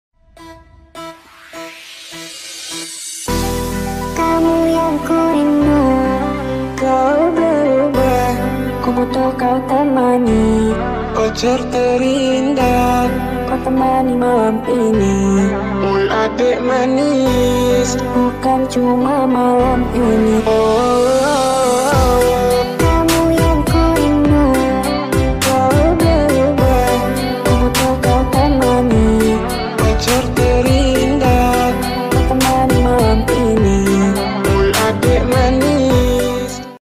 (Slowed+Reverb)